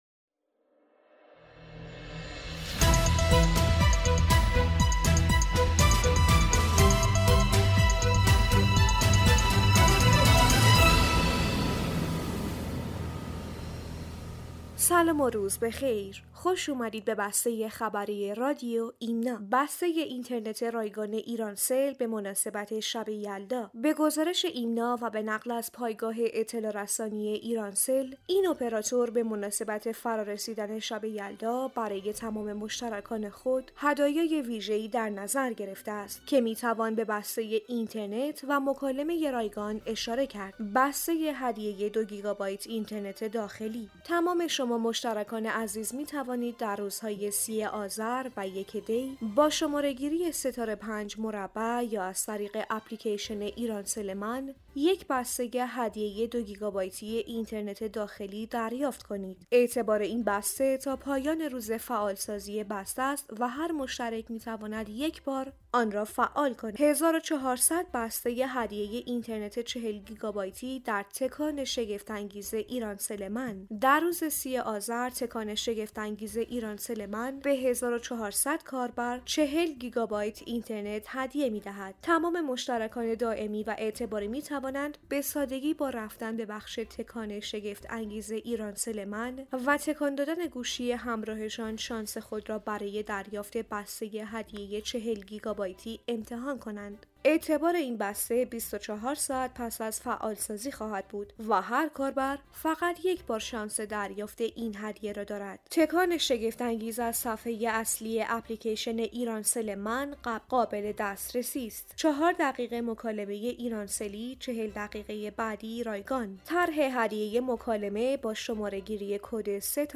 بسته خبری رادیو ایمنا/